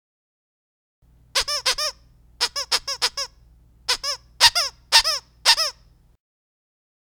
Squeaker Pillow Double-Voice - X-Large (12 Pack) - Trick
Double-voice squeakers make a sound both when they are pressed and when they are released.